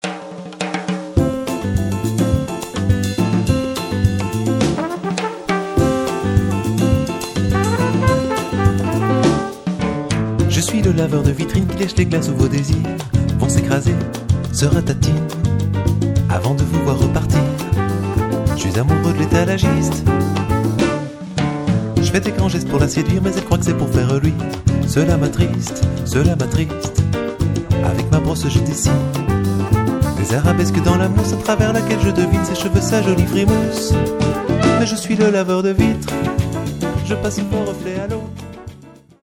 ラテン風味のピアノが走る